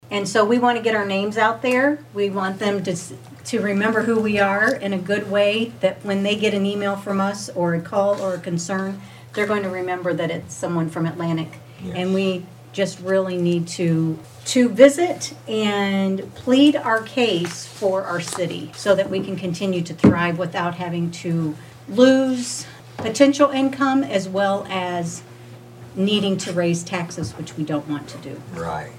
Mayor Grace Garrett reminded the Council of the importance of getting to Des Moines in January and having Council persons get to know the “decision makers, and what it’s going to take to continue to allow a rural America, specifically Iowa, to continue to thrive.”